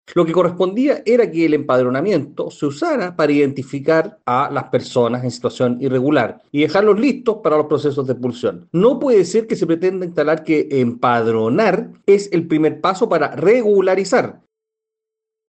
Por otro lado, desde Republicanos, el diputado Agustín Romero señaló que los procesos de empadronamiento son para identificar a migrantes irregulares y comenzar su proceso de expulsión, no para que sean regularizados.